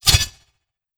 Whoosh Blade 003.wav